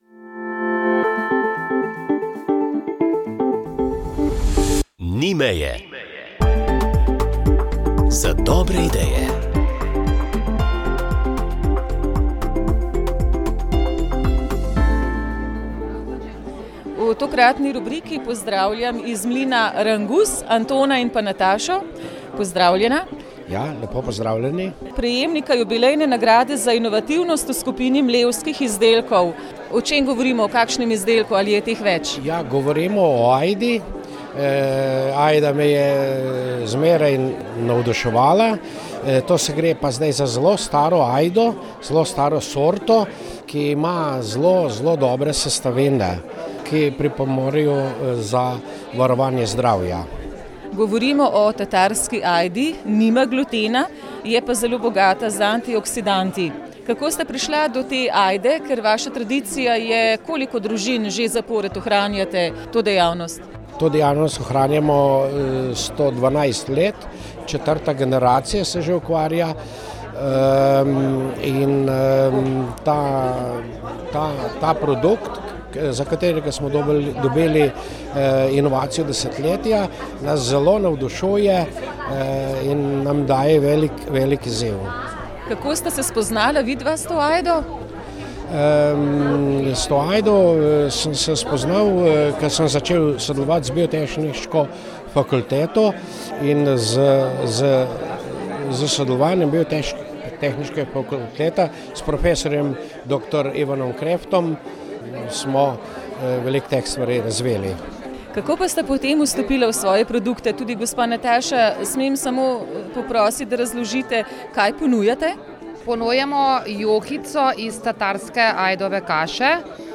Prisluhnili boste skladbam v izvedbi različnih slovenskih zborov in delom božične meditacije v interpretaciji Pavleta Ravnohriba.